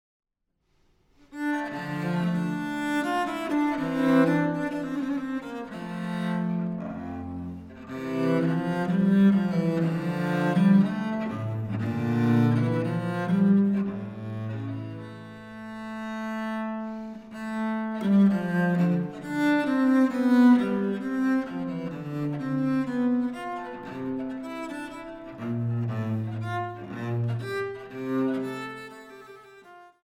Allemande (Warsaw, MS R221/377)